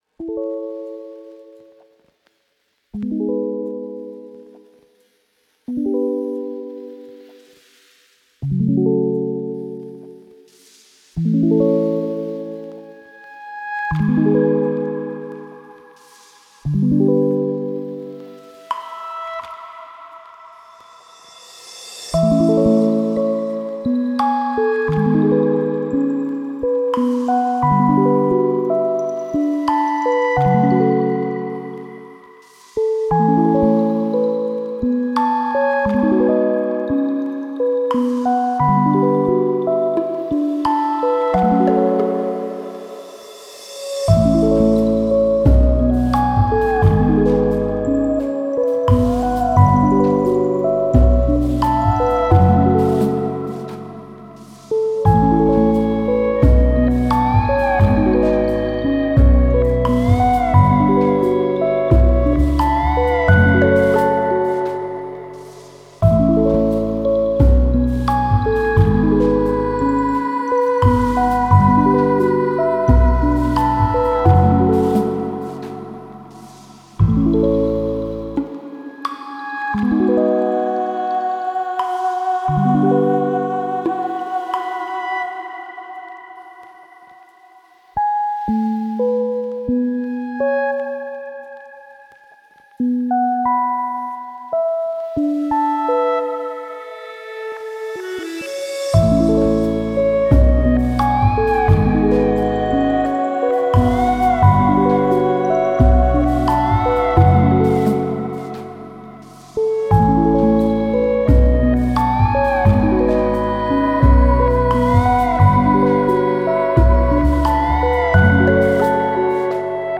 Tender, beautiful lullaby.